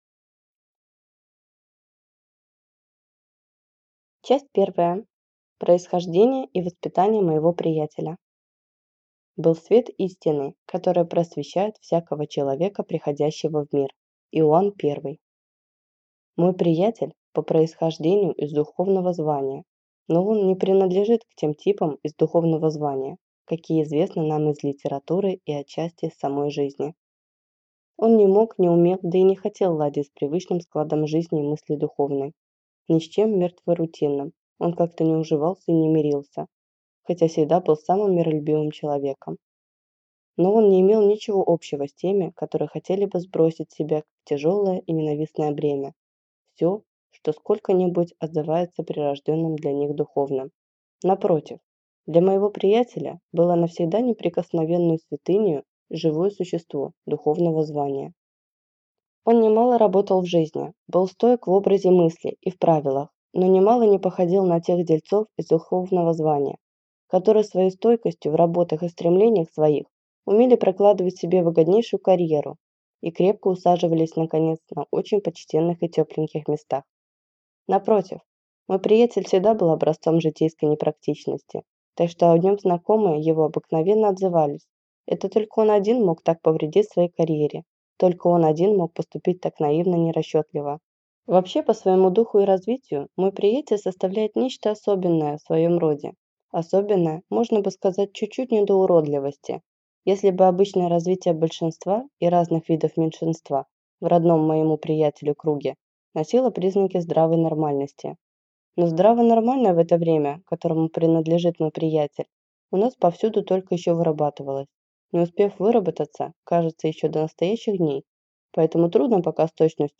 Аудиокнига Мой герой | Библиотека аудиокниг